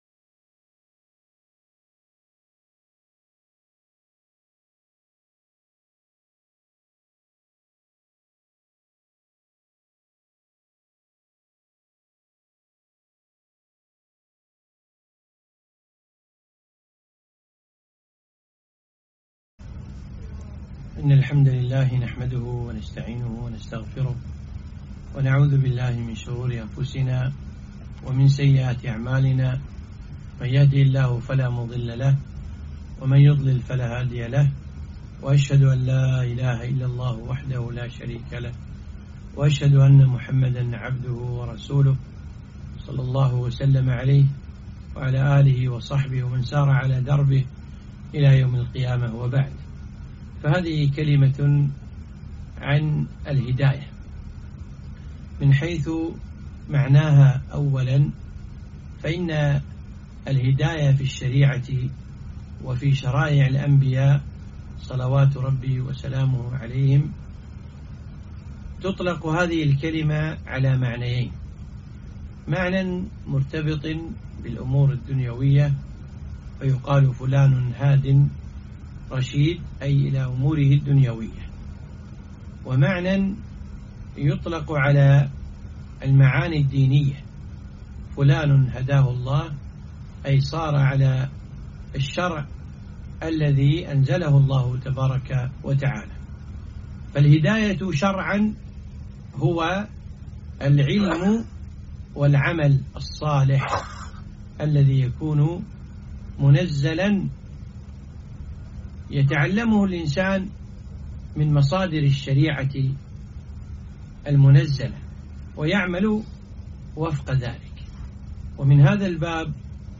محاضرة - الـهـدايـة